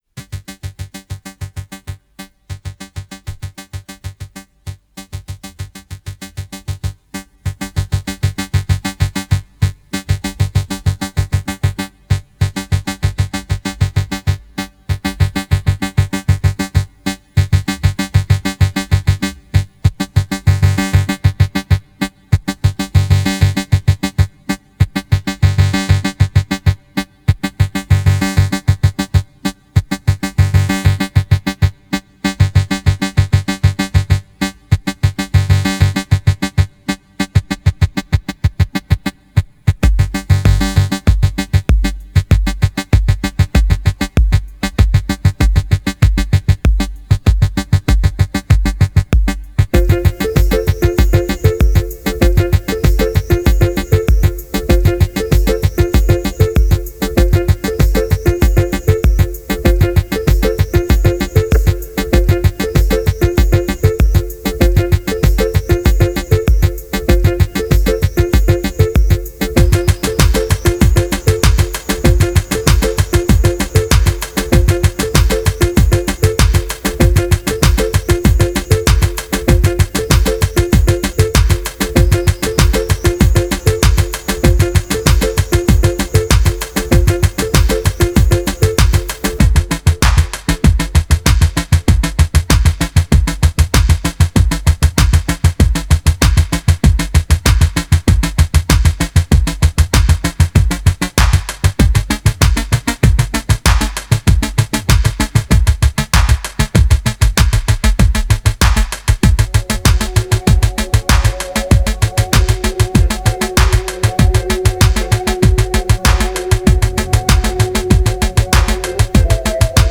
Electro, Downtempo, Synth Pop